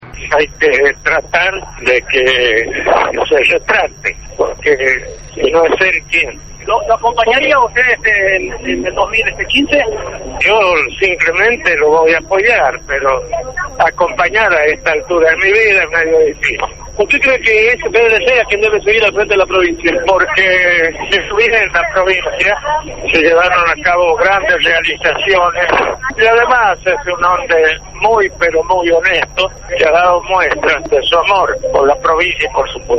Menem, quien no fue allí a votar en agosto ni en octubre, apareció este martes en La Rioja para participar del acto oficial por el 150º aniversario del asesinato del caudillo Ángel «Chacho» Peñaloza.